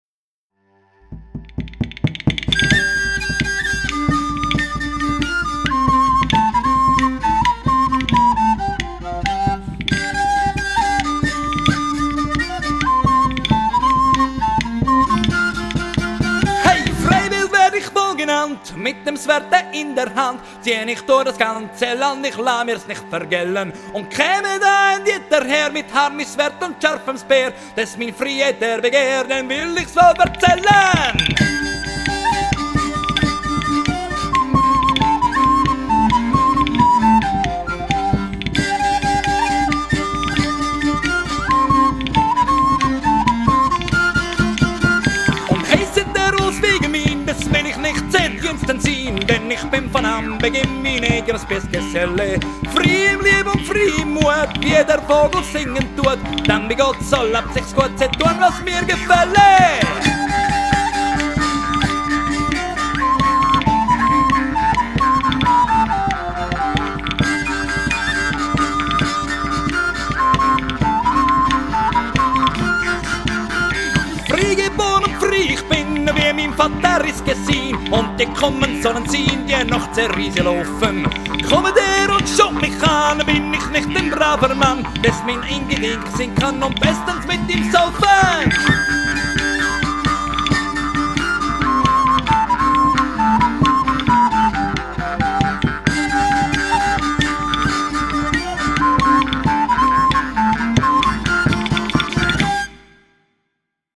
Reisläuferlieder aus dem Spätmittelalter